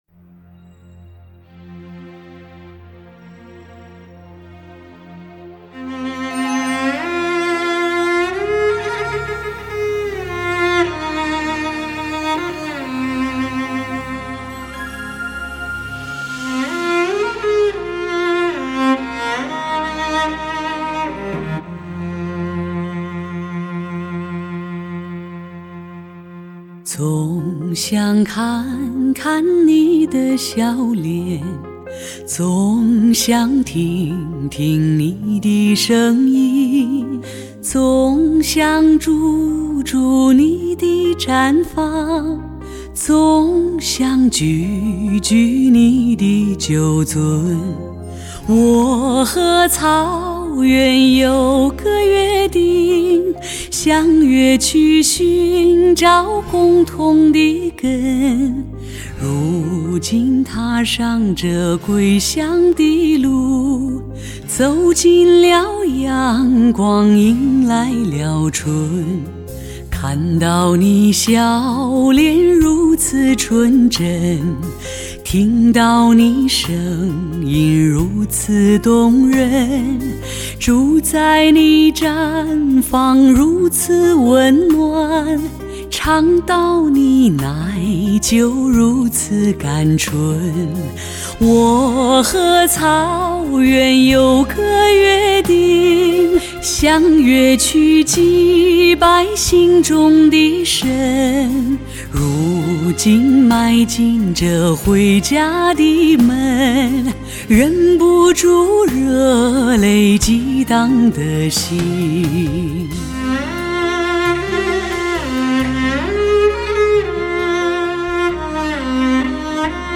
母带级音质 发烧新体验
全面恢复黑胶唱片的空气感和密度感
低音强劲有力，中音清晰丰满，高音柔和圆润，精确的乐器定位，清晰的人声，层次分明，声场辽阔。